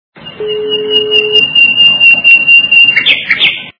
Птичка - Поет Звук Звуки Пташечка - Співае
» Звуки » Природа животные » Птичка - Поет
При прослушивании Птичка - Поет качество понижено и присутствуют гудки.